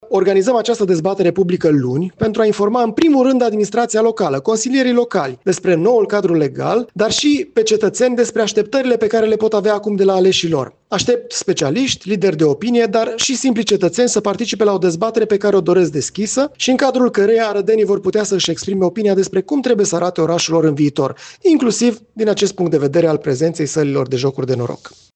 La rândul lui, prefectul județului Arad, Mihai Pașca, a anunțat că organizează luni o dezbatere publică despre acest subiect: